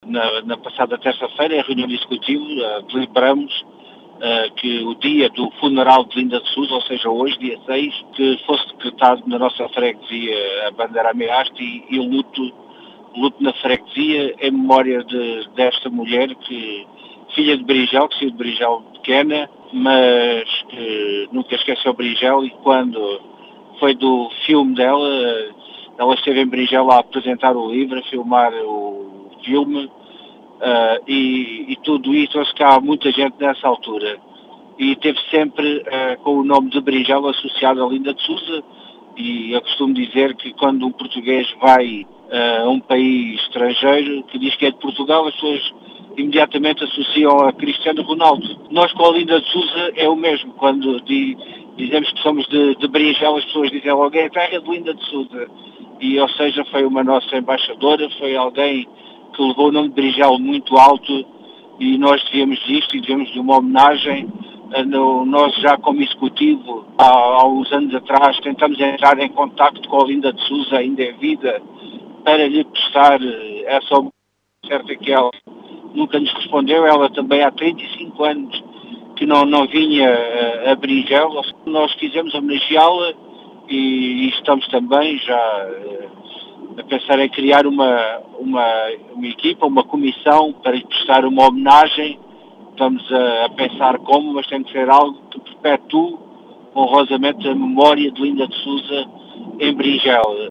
As explicações são de Vitor Besugo, presidente da junta de freguesia de Beringel, que diz tratar-se de uma “homenagem” a uma “embaixadora” de Beringel.